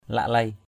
/laʔ-leɪ/ (t.) thấm tháp. mbeng oh laklei o biak O$ oH lKl] o% b`K ăn chẳng thấm tháp vào đâu cả. ngap mbeng oh jieng laklei hai ZP O$ oH j`$...
laklei.mp3